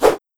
SFX_falloEspada4.wav